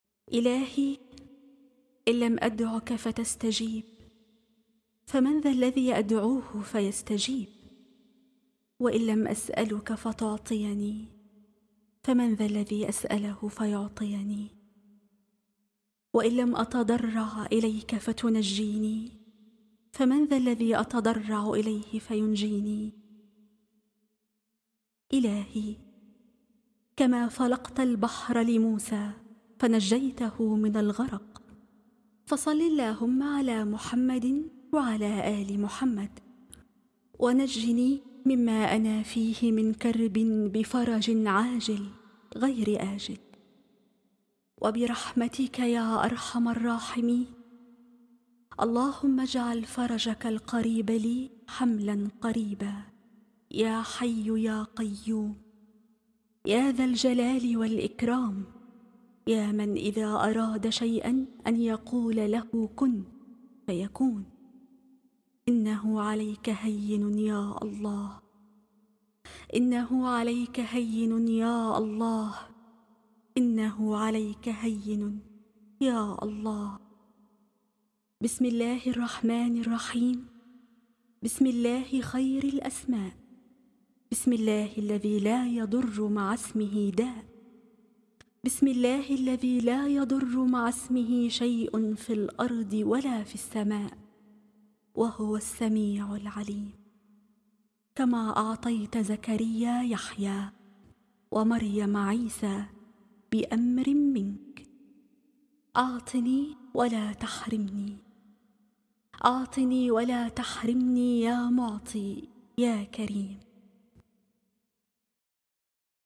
دعاء مؤثر مليء بالتضرع والالتجاء إلى الله تعالى، يعبر عن حالة من الشوق والثقة المطلقة في استجابة الدعاء وفرج الكرب. النص يركز على أن الله هو الملجأ الوحيد والمستجيب القريب، مع التوسل بأسمائه الحسنى وصفاته العليا.